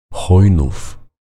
Chojnów [ˈxɔjnuf]
Pl-Chojnów.ogg.mp3